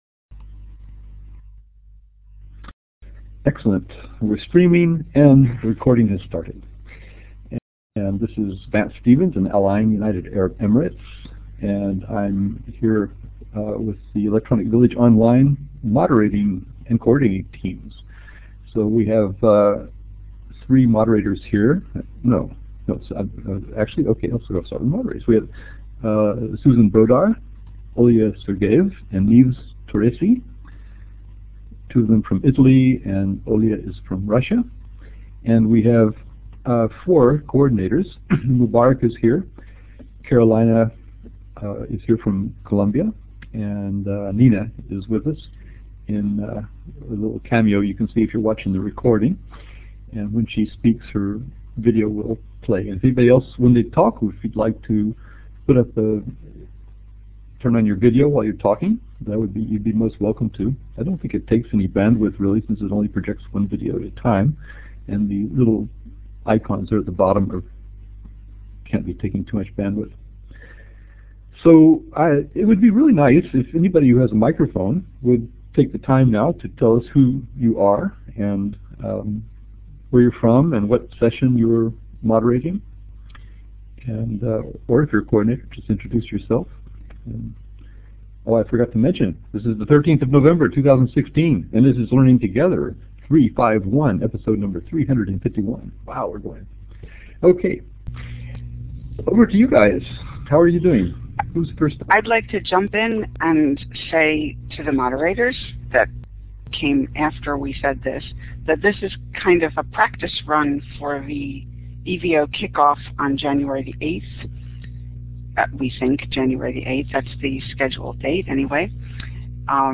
On Sunday, Nov 6, 2016, at the usual time of 1400 UTC, EVO moderators and coordinators gathered to discuss how things were going over halfway through the annual training of EVO moderators for the coming year.